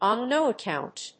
on nó accòunt